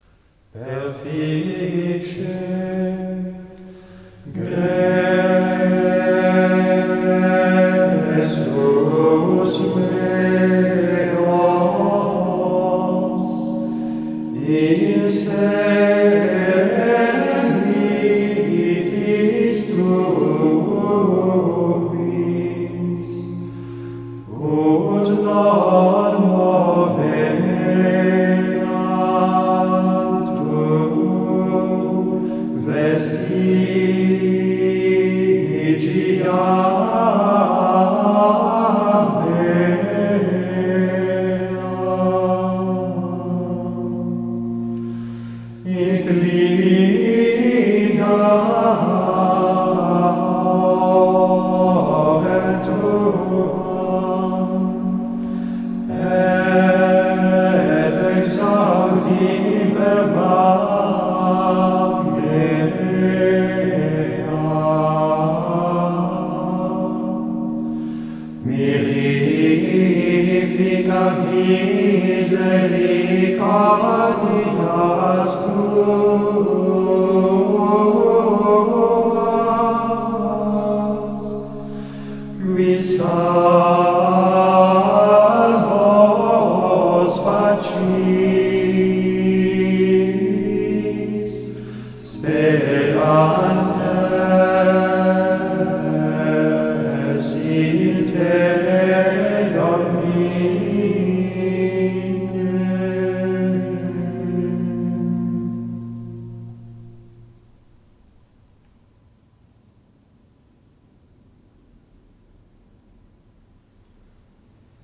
04-offertoire-5.mp3